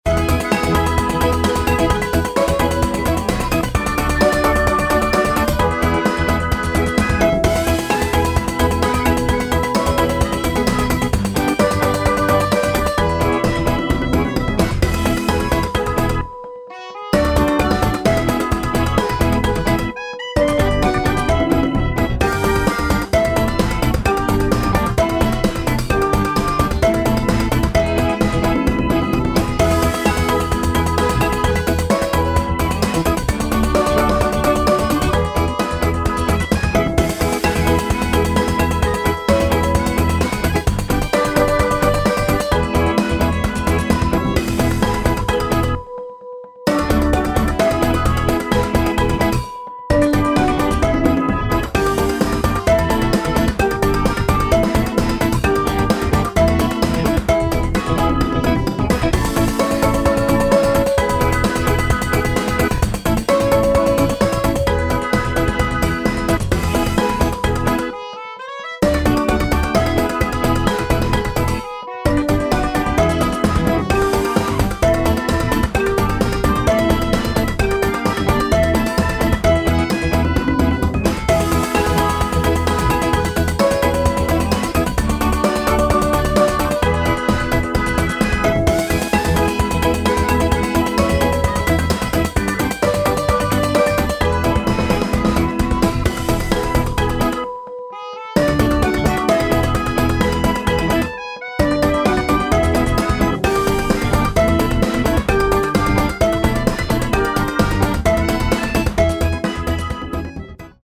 midi-demo 2